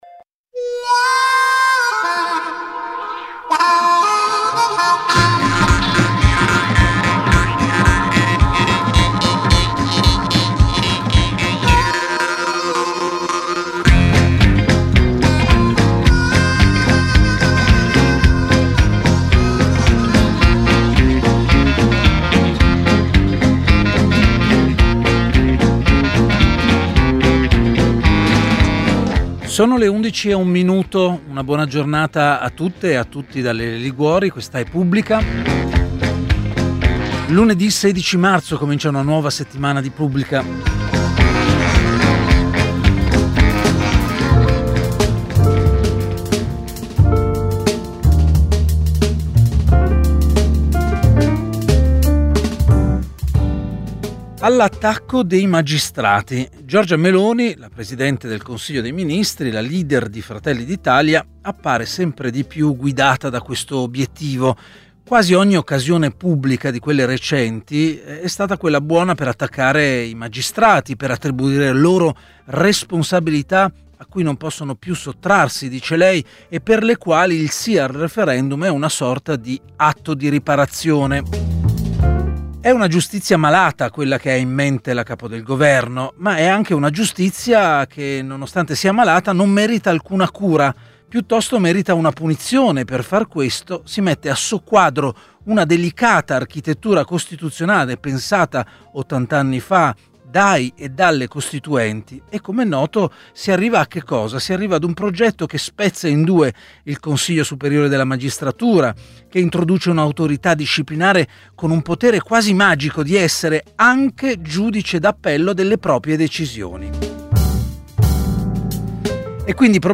Pubblica ha ospitato oggi Carlo Galli, filosofo della politica, docente di storia delle dottrine politiche all’università di Bologna, autore di “Tecnica” (Il Mulino, 2026).